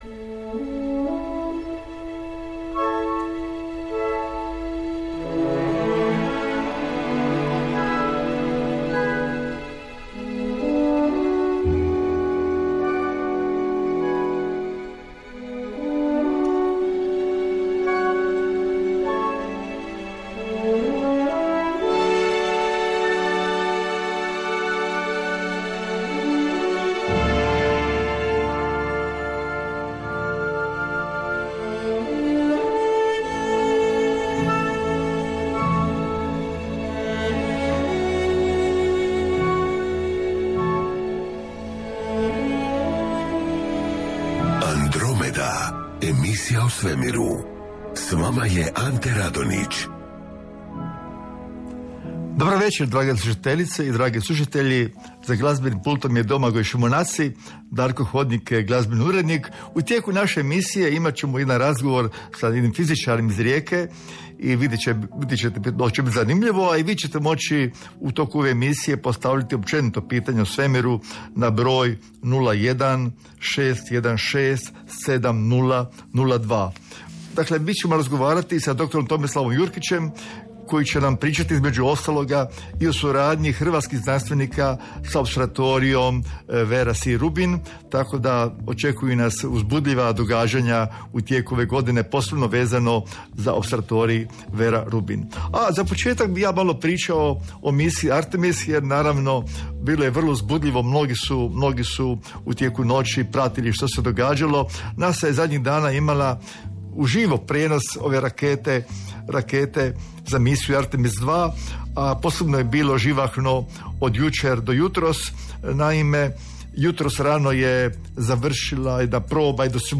Uz stručnog suradnika odgovara se na pitanja slušatelja, komentira vijesti iz astronomije, astrofizike i astronautike.